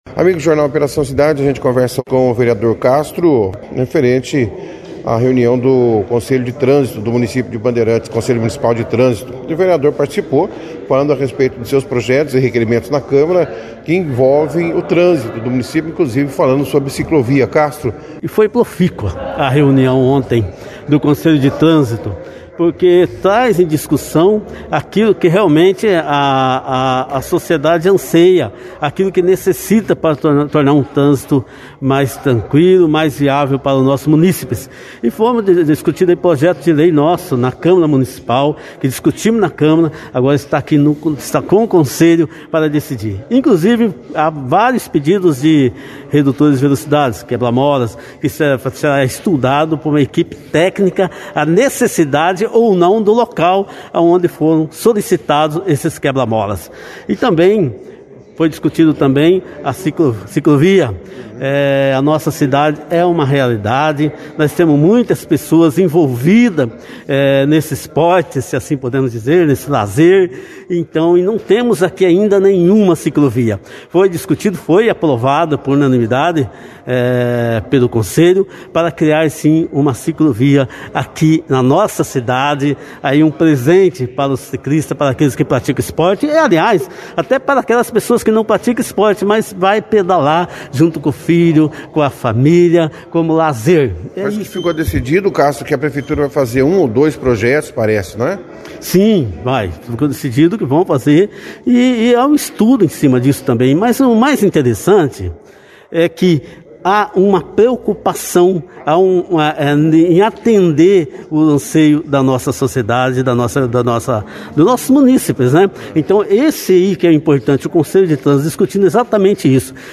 A reunião foi destaque da 2ª edição do jornal Operação Cidade desta quinta-feira, 12/08, com a partcipação do vereador Castro, Secretário de Obras Jonas Avilla e do Prefeito Jaelson Matta.